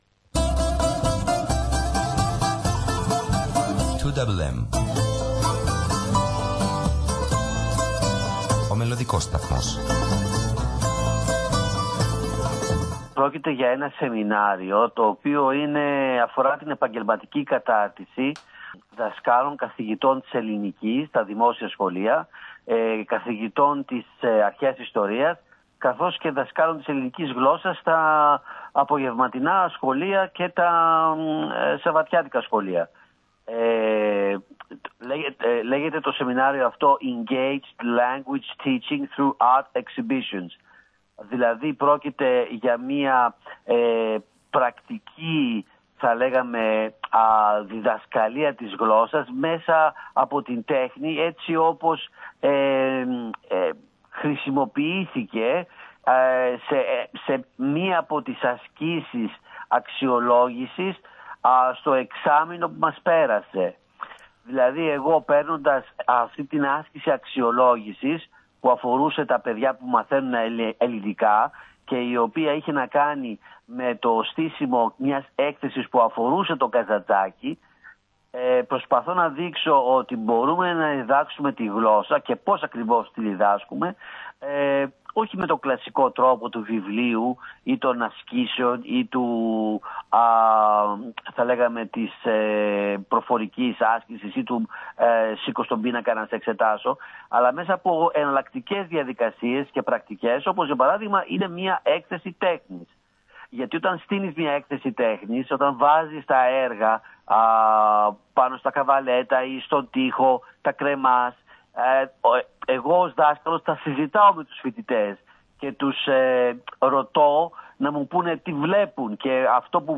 συνέντευξη
ραδιοφωνική εκπομπή